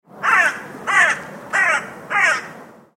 دانلود صدای قار قار کلاغ چهار بار از ساعد نیوز با لینک مستقیم و کیفیت بالا
جلوه های صوتی